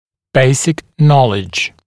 [‘beɪsɪk ‘nɔlɪʤ][‘бэйсик ‘нолидж]базовые знания